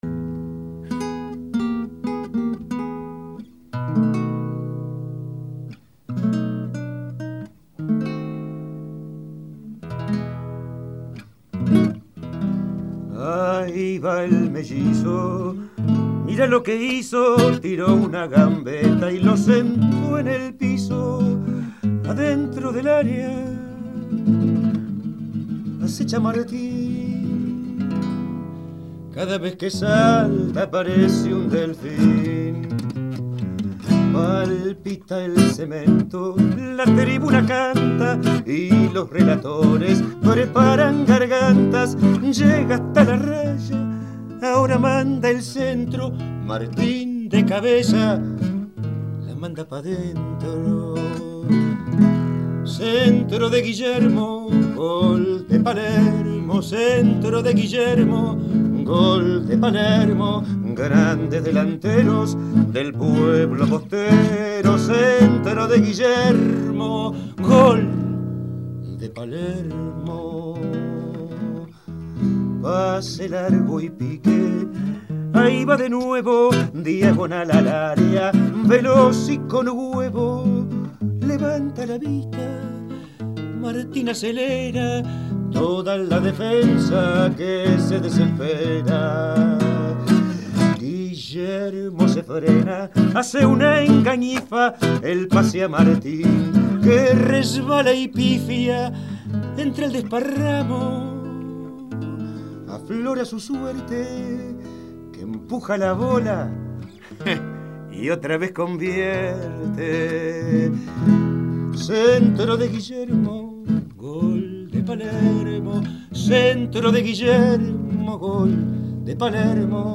cantó tres tangazos…